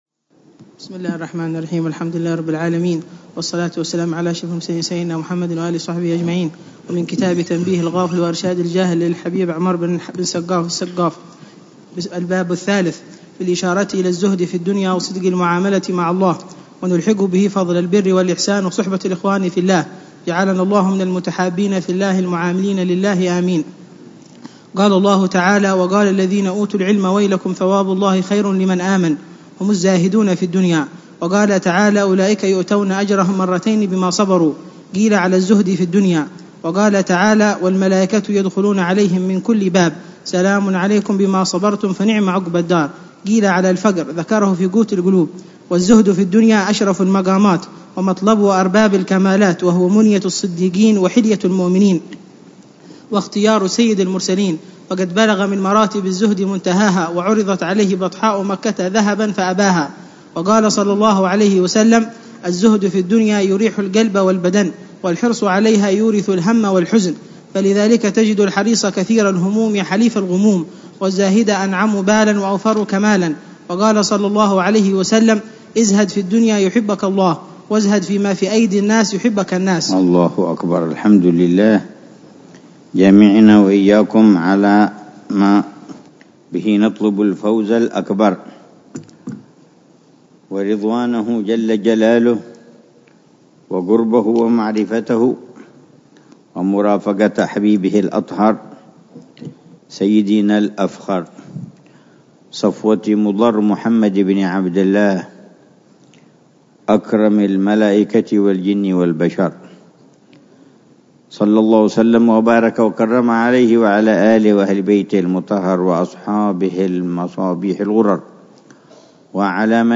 الدرس الرابع عشر من شرح العلامة الحبيب عمر بن محمد بن حفيظ لكتاب : تنبيه الغافل وإرشاد الجاهل للإمام الحبيب : عمر بن سقاف بن محمد الصافي السقا